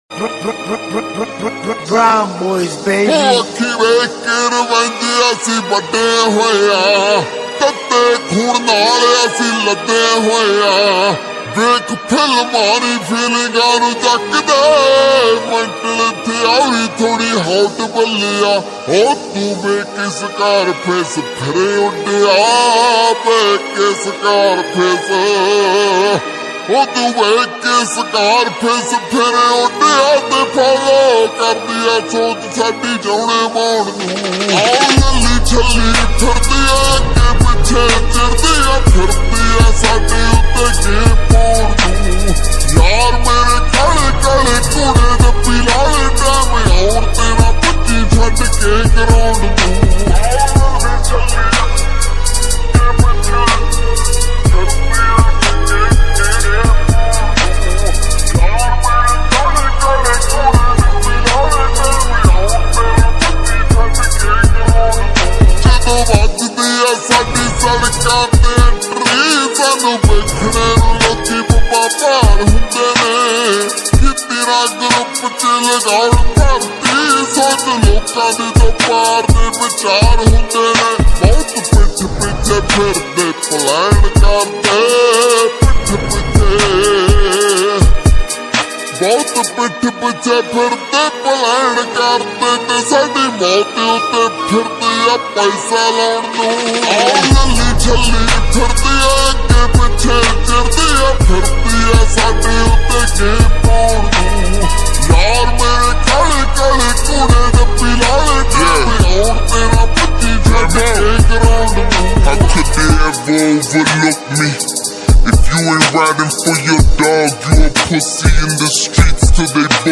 SOLVED REVERB